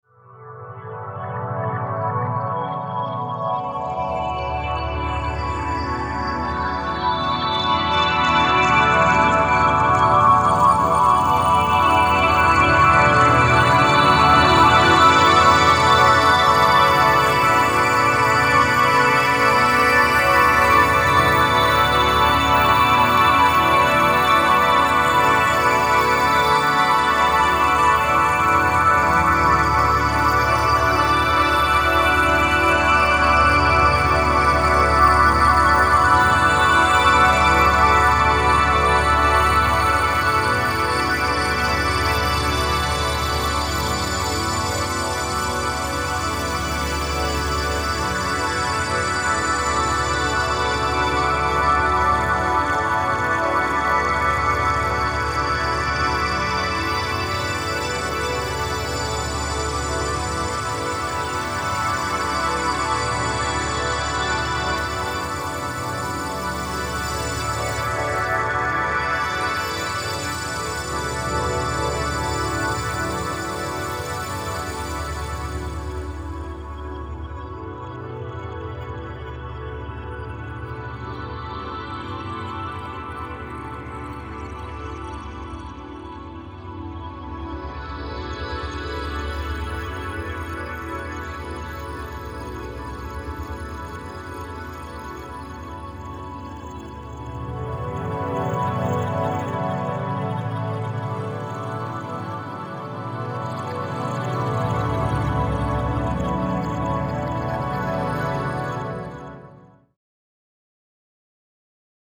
pads
- Long Spheric Ambient Pads -